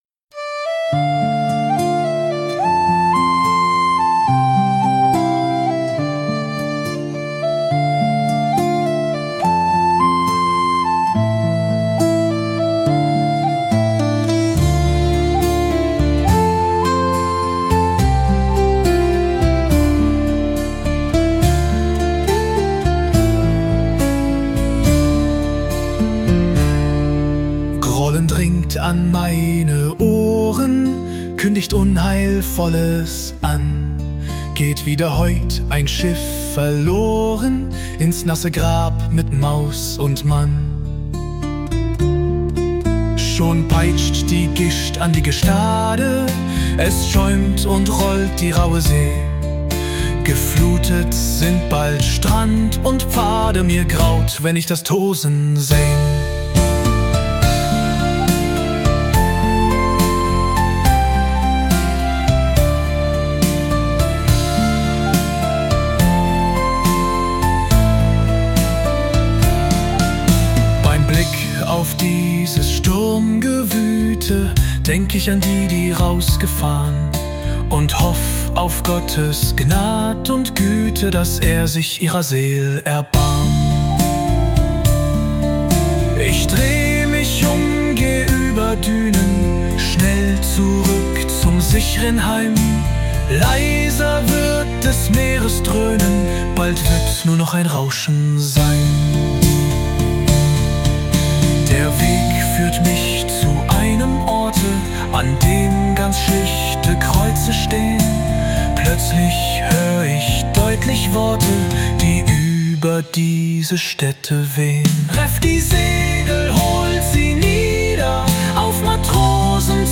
Der Text wurde mit einem bezahlten KI-Modell und entsprechenden Prompts zu folgendem Song umgesetzt: